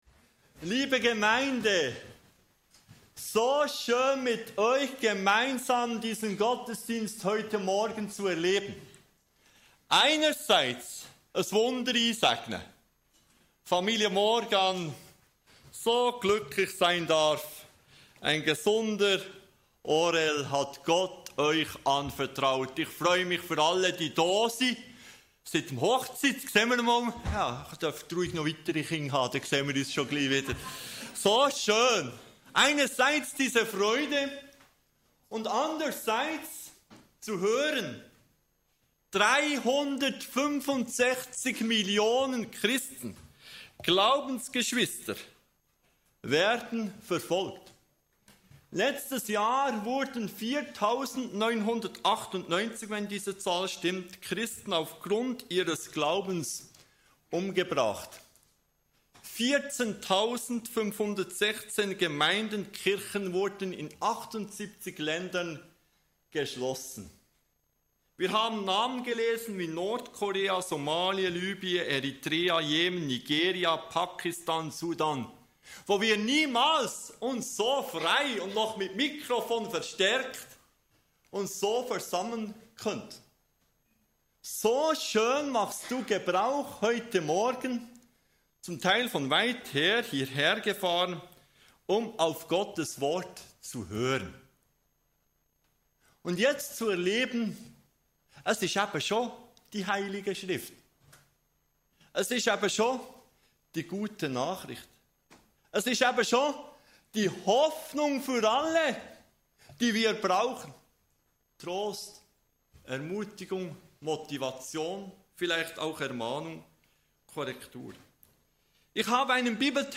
Gott hat uns keinen Geist der Furcht gegeben, sondern der Kraft, Liebe und Besonnenheit. In dieser Predigt entdecken wir, wie dieser Geist uns in Zeiten von Freude und Verfolgung stärkt und uns ermutigt, unseren Glauben mutig zu leben.
Kategorie: Gottesdienst